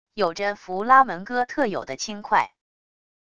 有着弗拉门戈特有的轻快wav音频